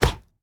ancientpig_vanish.ogg